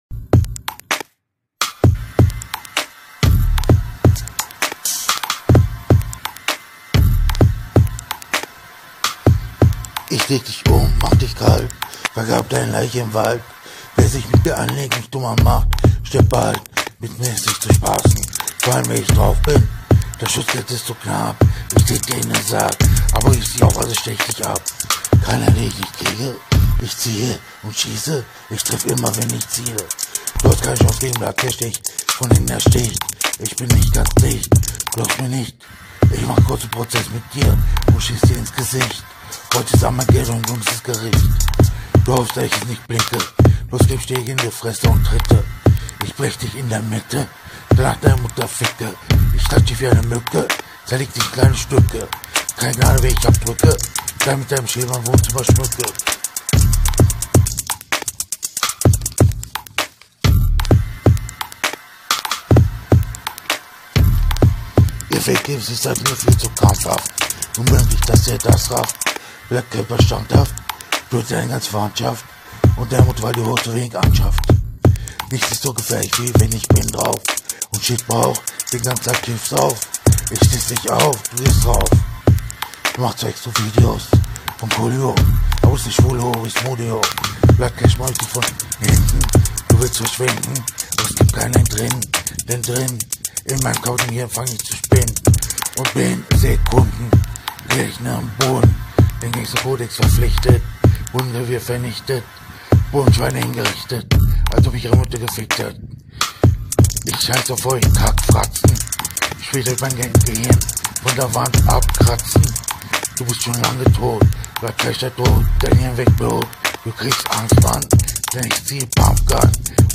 möchte rapsong vorstellen